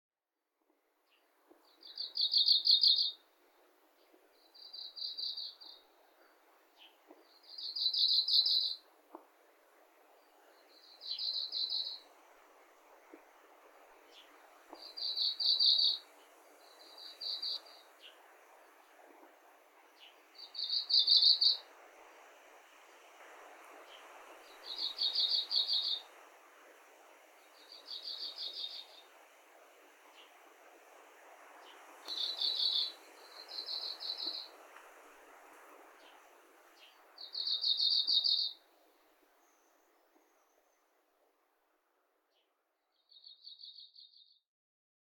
【録音5】 　2023年8月6日　編笠山
録音5はメボソムシクイの３音節のさえずりではないかと思うのだがいかがでしょうか？